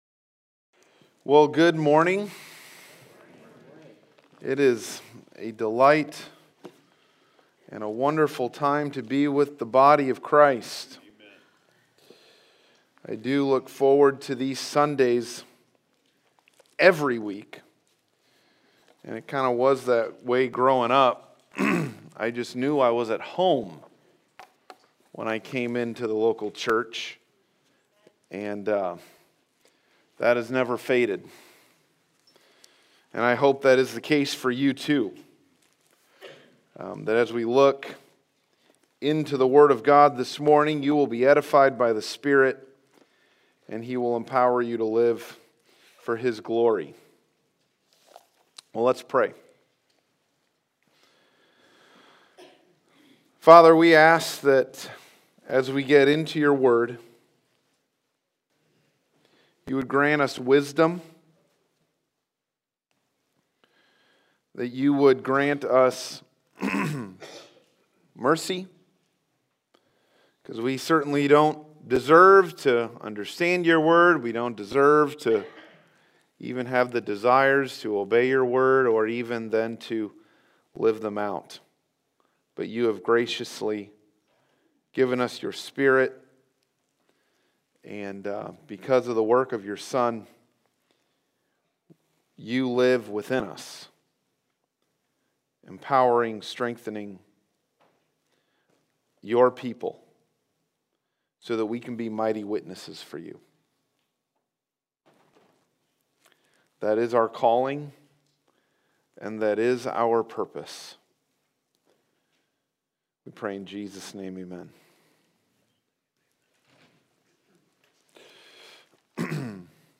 Passage: Acts 5:17-42 Service Type: Sunday Morning